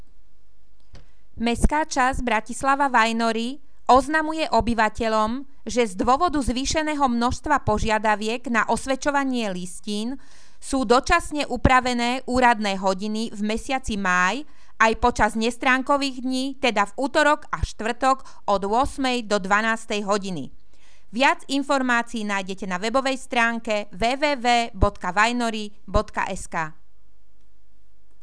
Hlásenie miestneho rozhlasu 15.,16.,18.5.2015